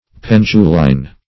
Search Result for " penduline" : The Collaborative International Dictionary of English v.0.48: Penduline \Pen"du`line\, n. [F. See Pendulum .]